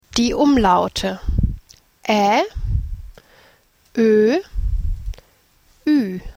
• Ä is pronounced like the “a” in “apple” or the “e” in “ever.”
• Ö is pronounced like the “ir” in “girl” except with less of an “r” sound.
• Ü is pronounced similar to the “eau” in the word “beautiful” but with rounded lips.
umlaute-german.mp3